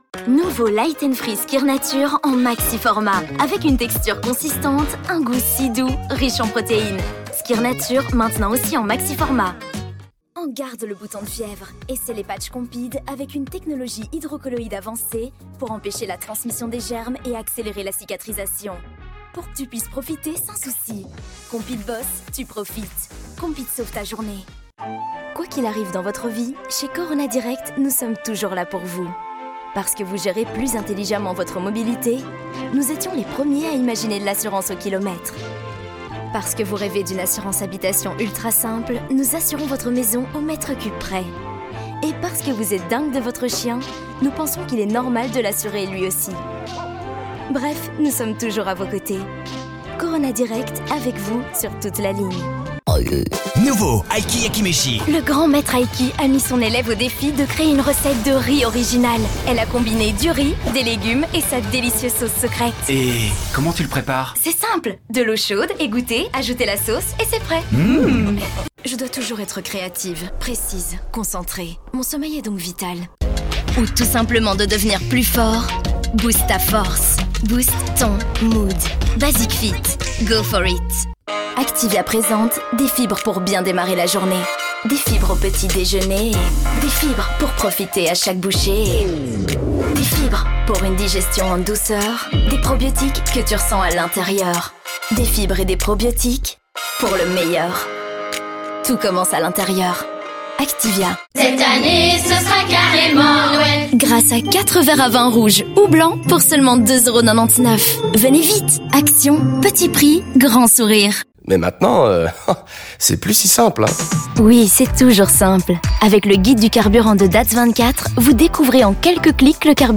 Jeune, Naturelle, Enjouée, Urbaine, Cool
Commercial